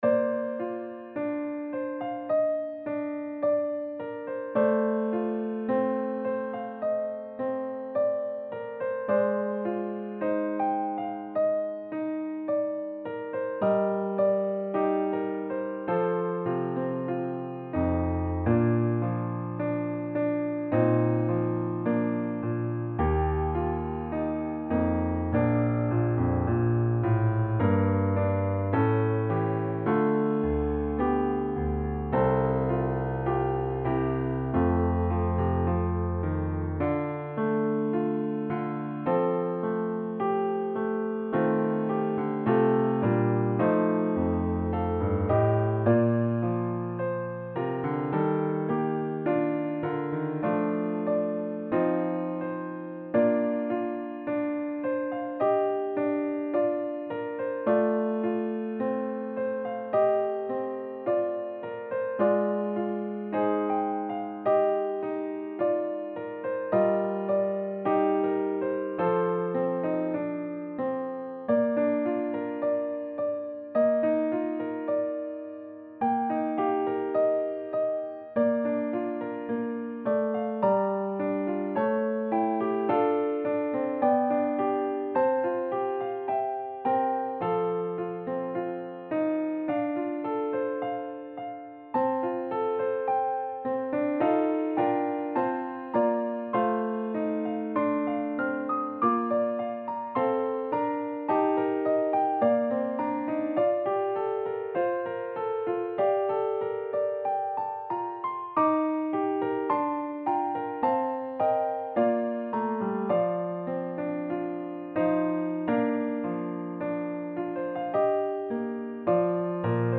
lyrical piano arrangement
hymn tune